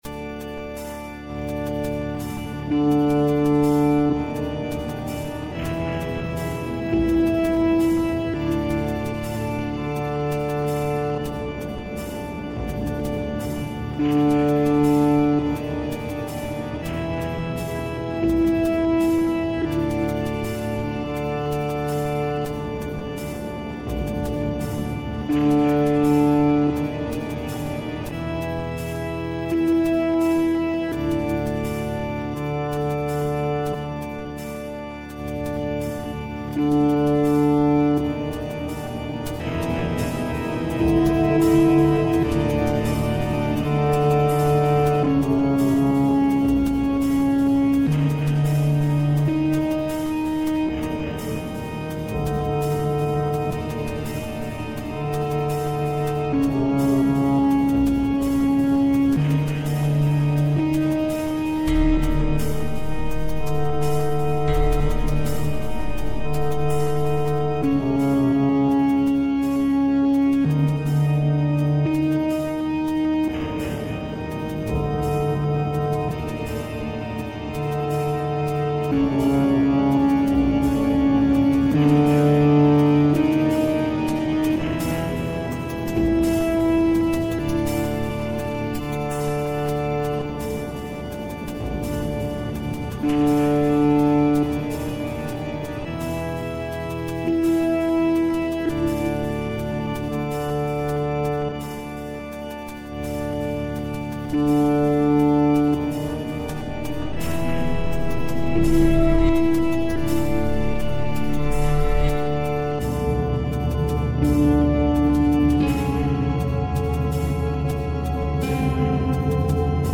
• Жанр: Электронная
тусклый свет сансары, электронный эмбиент